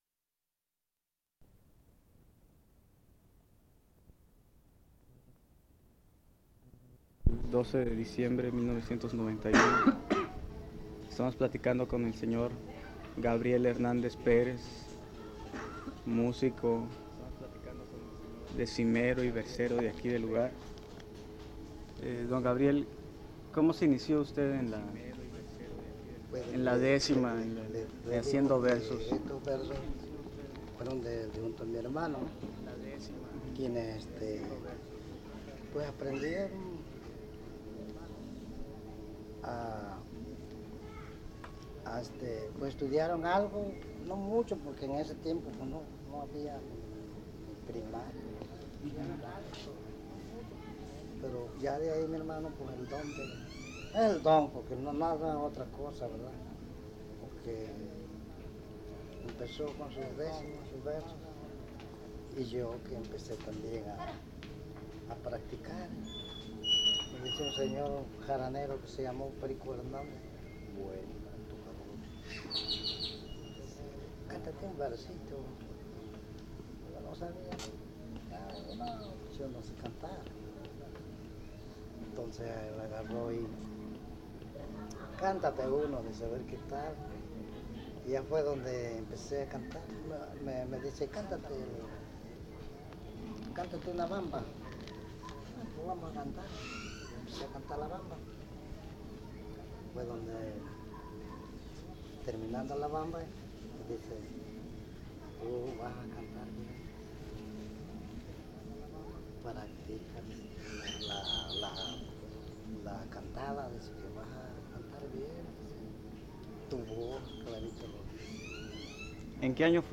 Comoapan, San Andrés, Veracruz
Entrevista